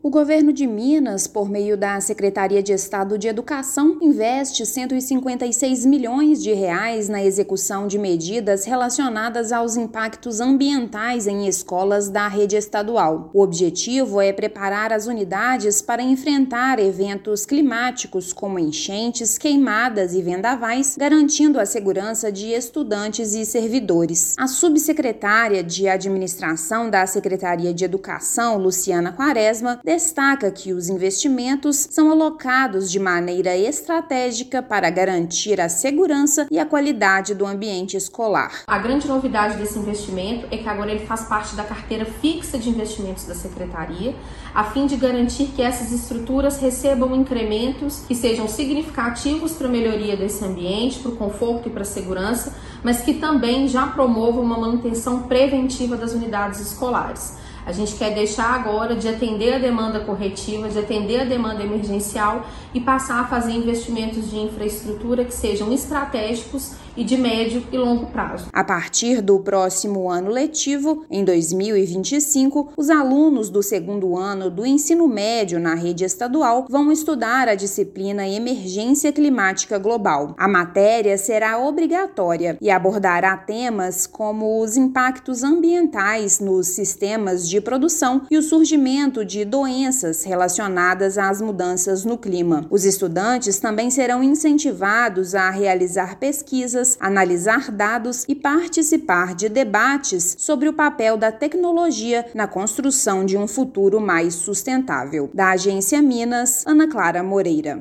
Além dos recursos para infraestrutura, estudantes vão contar com novos conteúdos curriculares sobre mudanças climáticas. Ouça matéria de rádio.